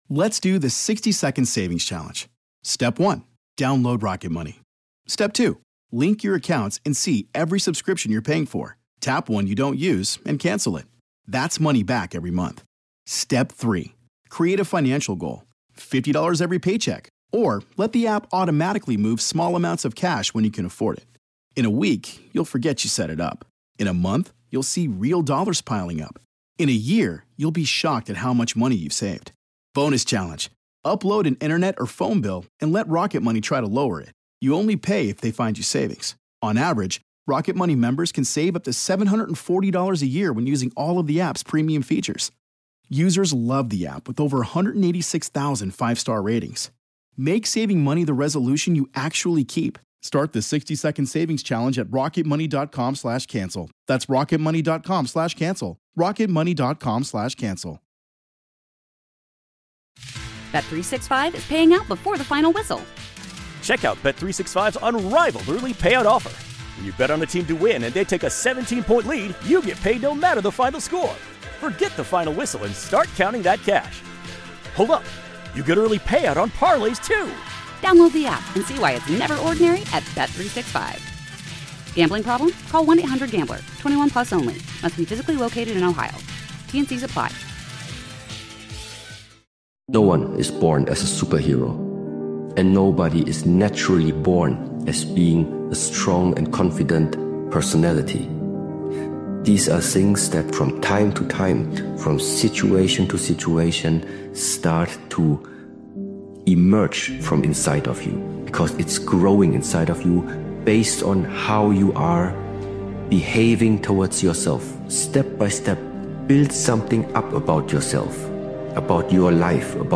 Powerful Motivational Speech Video is a hard-hitting and no-excuses motivational video created and edited by Daily Motivations. This powerful motivational speeches compilation is a wake-up call to stop negotiating with comfort and start moving with intent.